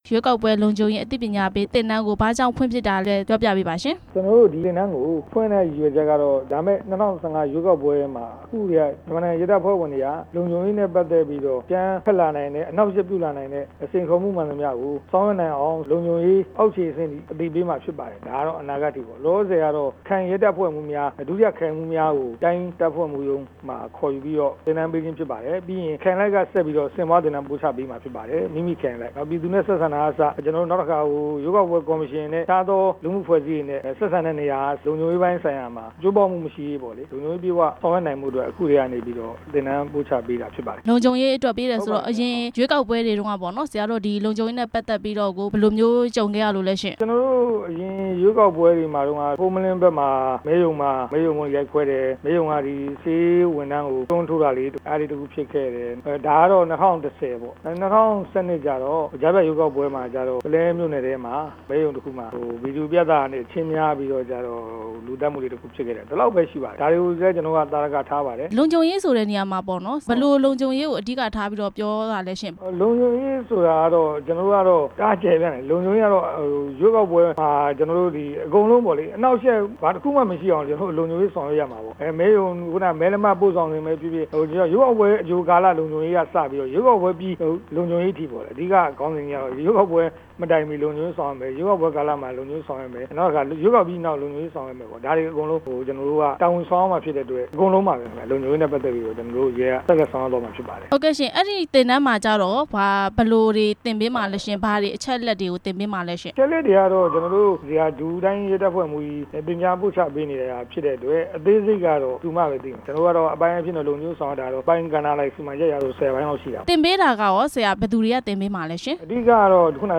၂ဝ၁၅ ရွေးကောက်ပွဲဆိုင်ရာ လုံ ခြုံရေးအသိပညာပေး သင်တန်း အကြောင်း မေးမြန်းချက်